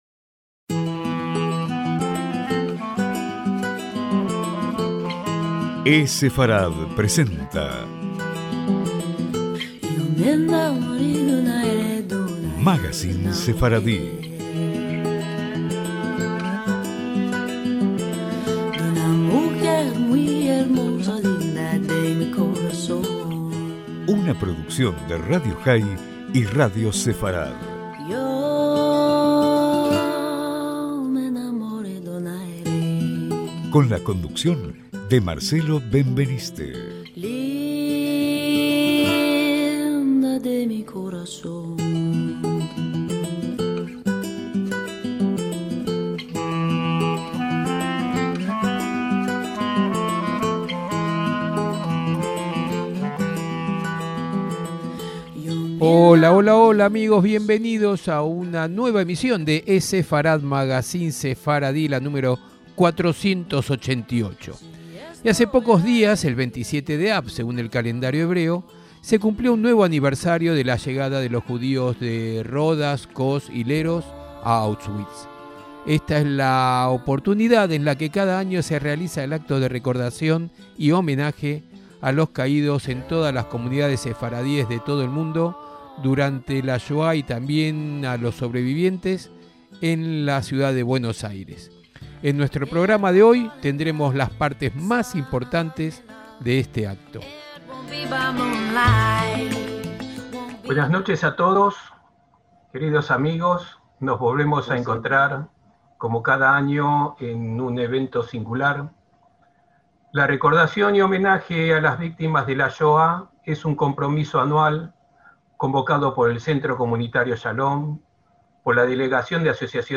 Esa es la oportunidad en la que cada año se realiza el acto de recordación y homenaje a los caídos en todas las comunidades sefardíes de todo el mundo durante la Shoá y también a los sobrevivientes. En nuestro programa de hoy tendremos las partes más importantes de este acto.